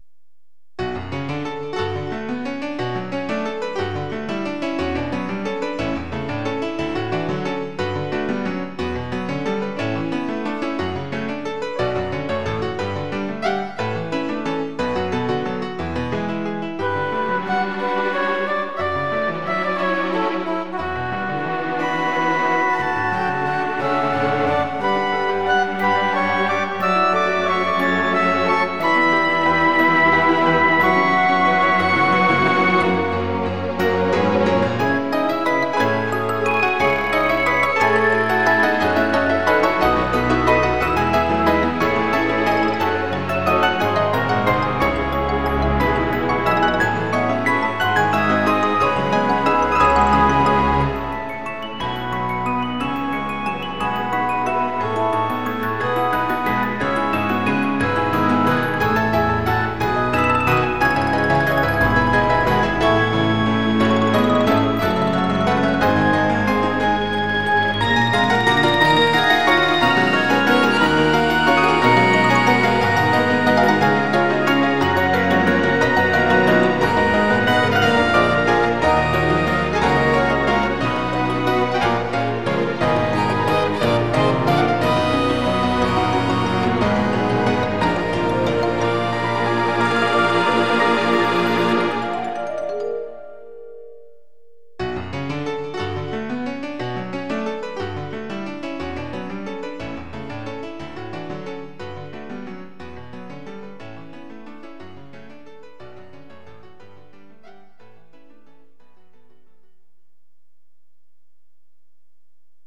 CD版とは若干雰囲気も違うかもしれません（マスタリングの有無 ◆ 淡白な悲愴曲？だんだん転調して上へ昇る。
21:44 分類 インストゥルメンタル